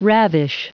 Prononciation du mot ravish en anglais (fichier audio)
Prononciation du mot : ravish